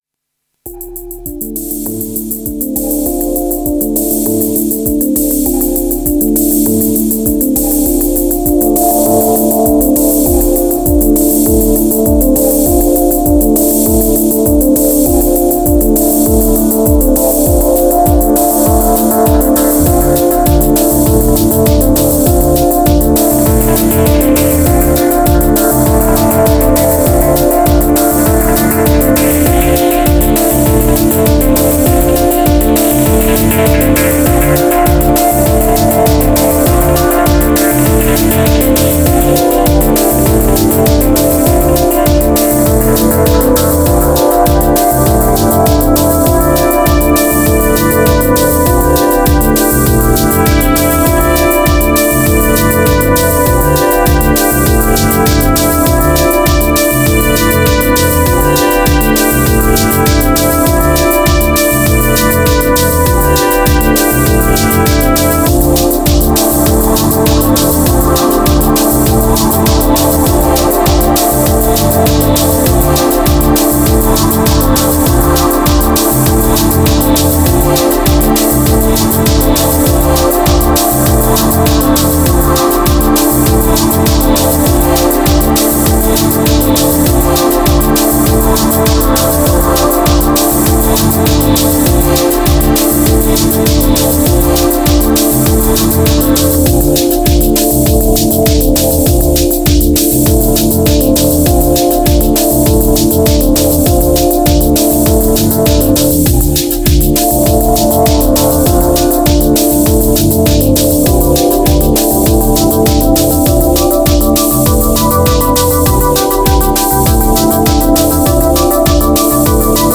962📈 - 95%🤔 - 100BPM🔊 - 2025-08-08📅 - 975🌟
Heat Kicks Moon Modal Empire Groove Bass Attic Unity